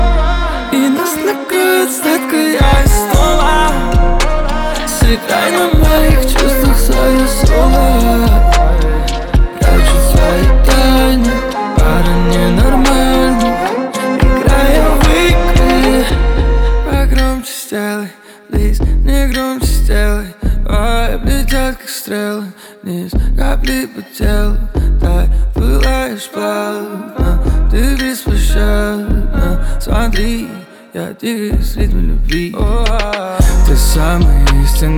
Жанр: Поп музыка / Танцевальные / Русский поп / Русские
Pop, Dance